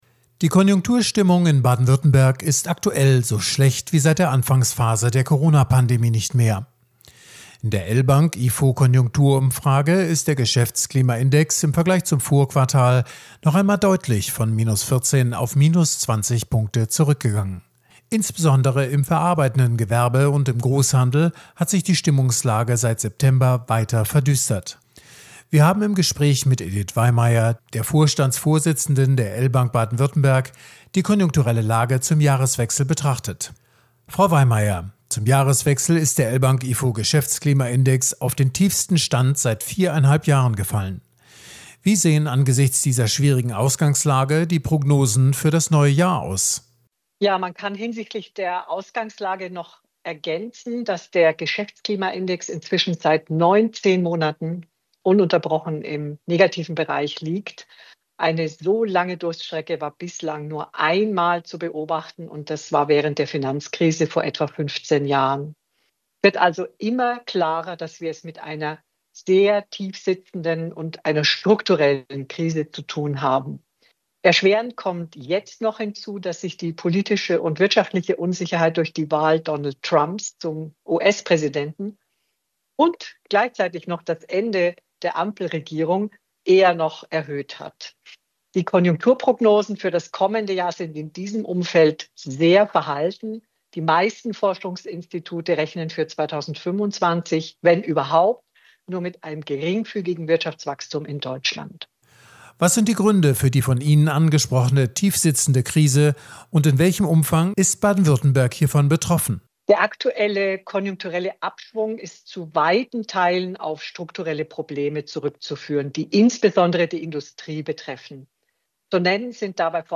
Sendefähige Interviews, Statements und O-Töne zu aktuellen Themen
Konjunktur-Interview